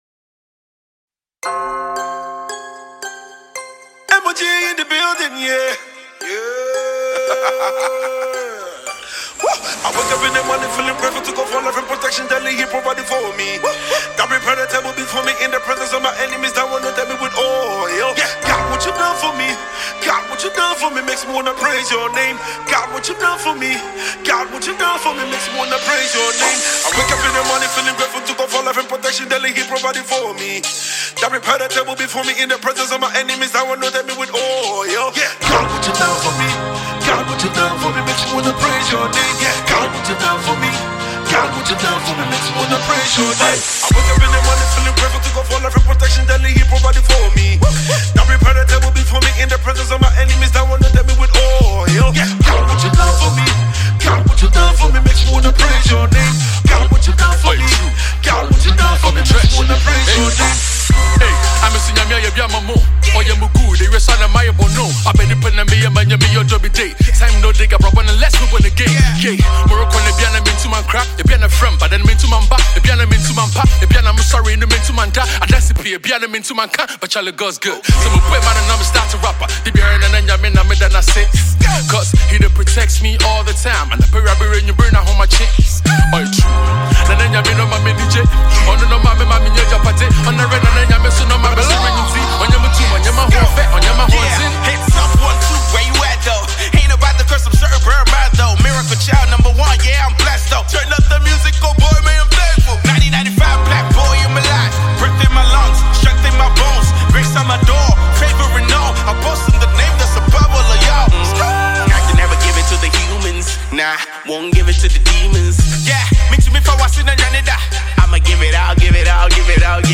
January 17, 2025 Publisher 01 Gospel 0
contemporary gospel singer